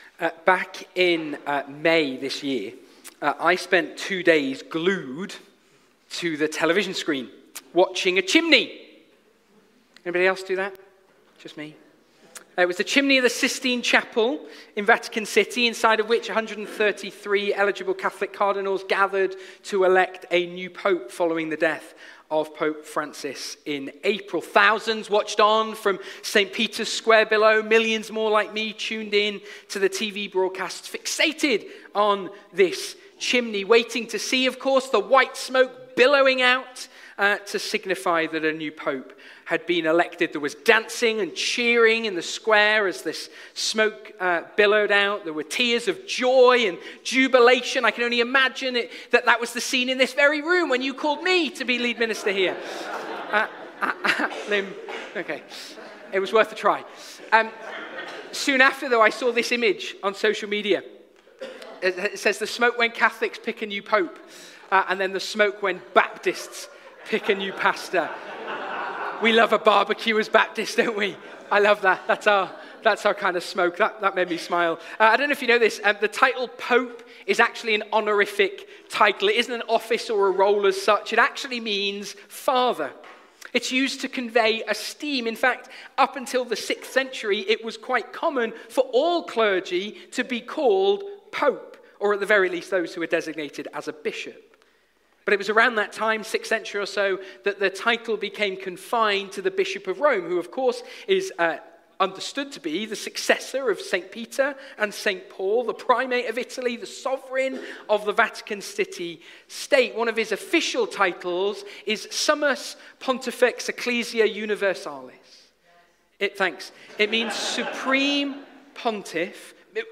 Live stream Passage: Hebrews 4:14-5:14 Service Type: Sunday Morning